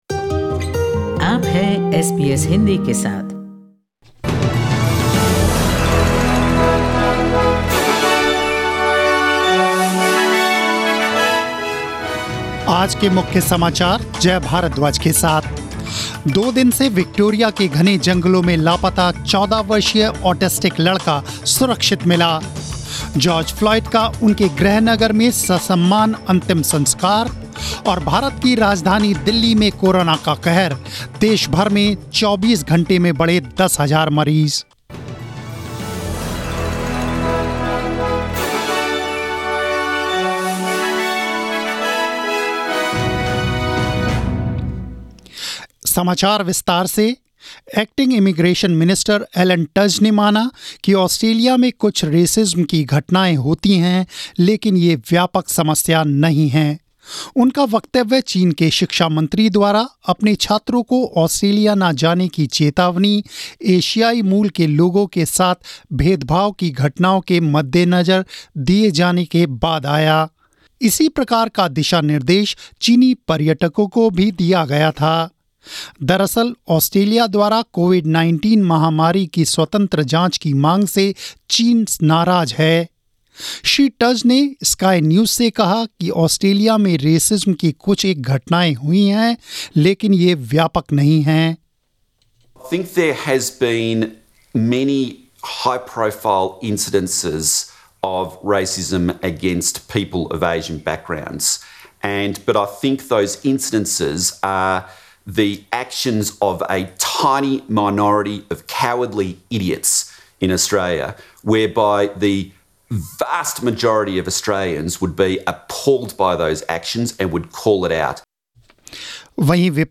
News in Hindi 10th June 2020